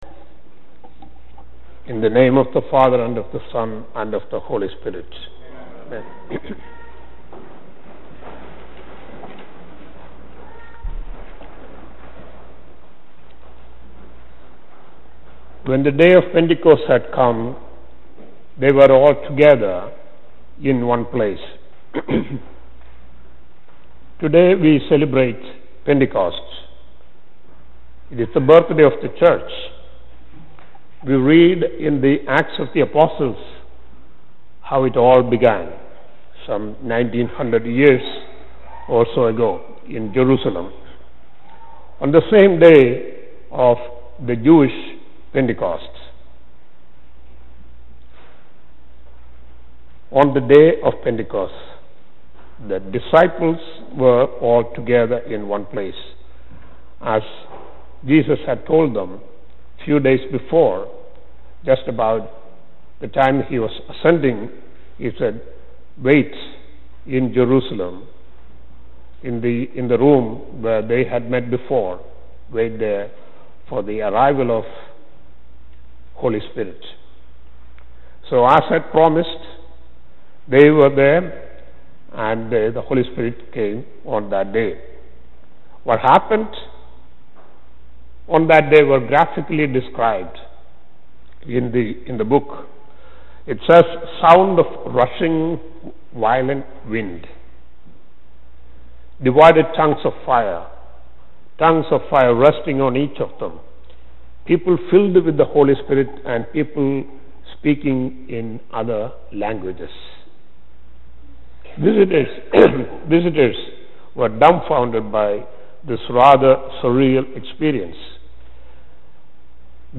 Saint Peter's Episcopal Church :: Phoenixville, PA
Sermon